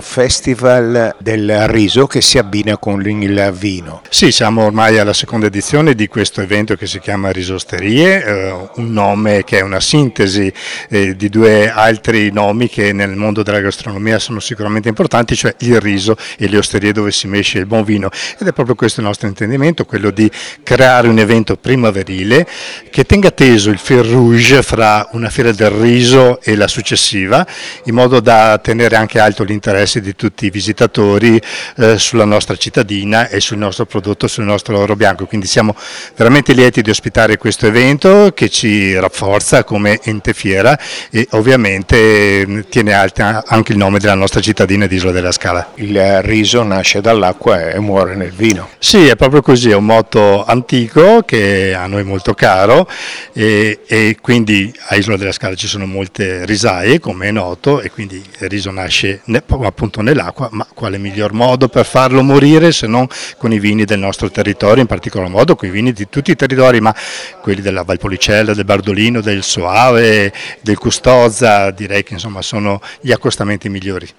Qui di seguito le interviste raccolte nella giornata di presentazione